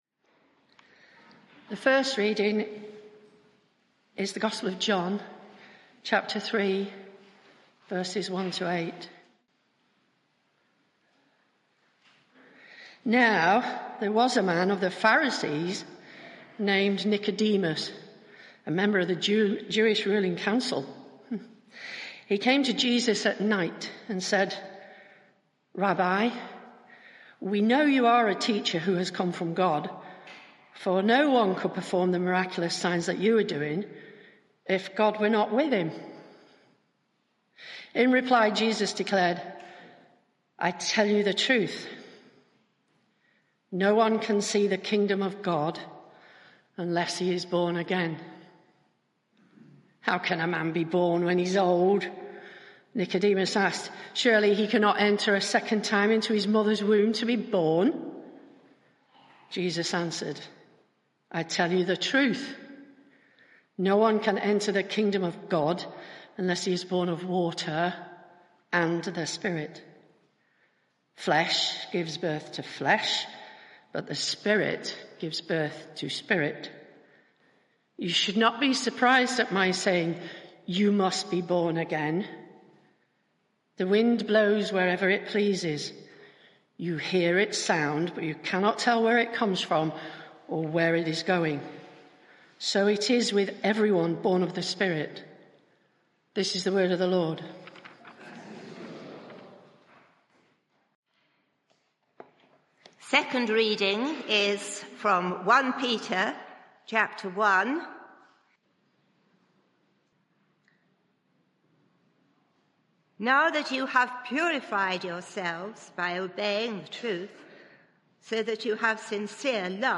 Media for 11am Service on Sun 21st May 2023 11:00 Speaker
Sermon (audio)